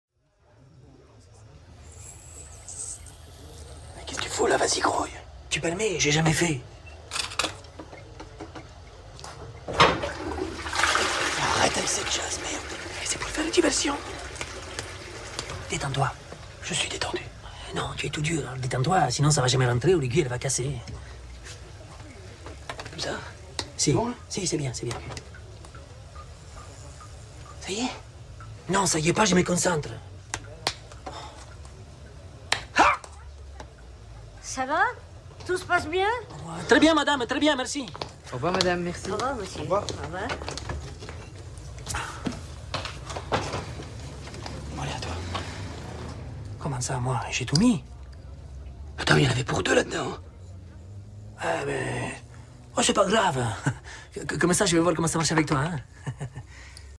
Mais un célèbre acteur belge y est. Le thème du film se passe dans le milieu d'un sport, très populaire l'été.